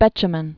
(bĕchə-mən), Sir John 1906-1984.